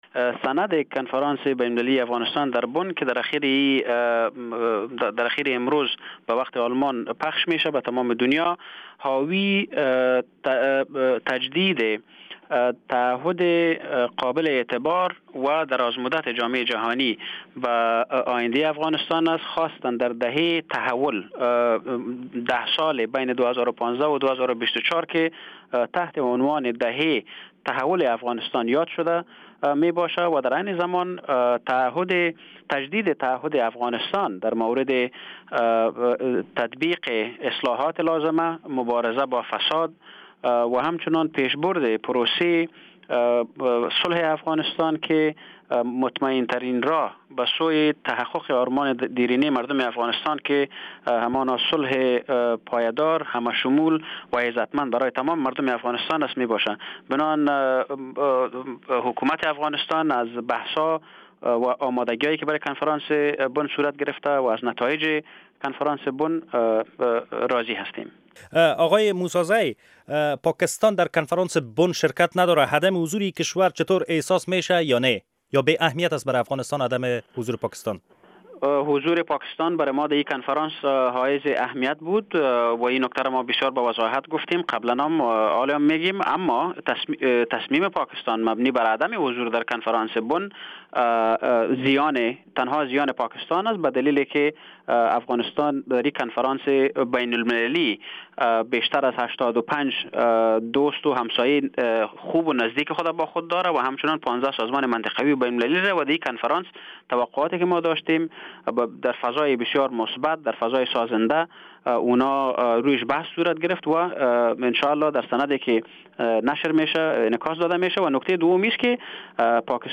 مصاحبه با جانان موسی زی در مورد چگونگی جلب کمک های بین المللی به افغانستان